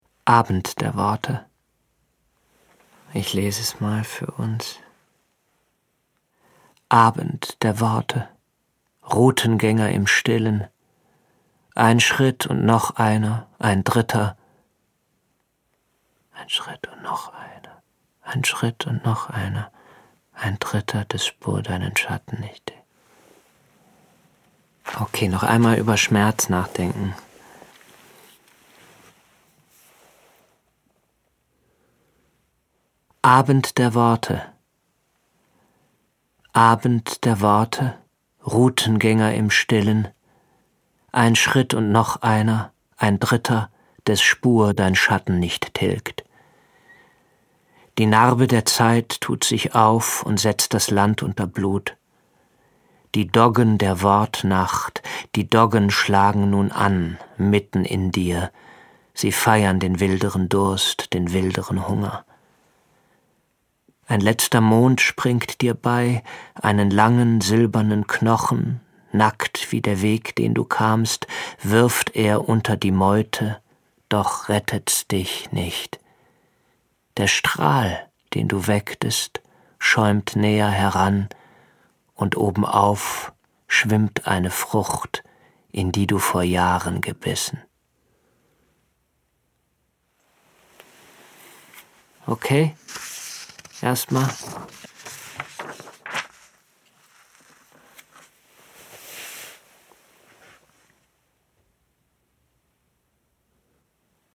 Zu diesem Anlass hat Jens Harzer eine Auswahl von Celans Gedichten vor dem Mikrofon neu interpretiert.
Mitarbeit Sprecher: Jens Harzer